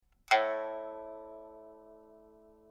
pipa1.mp3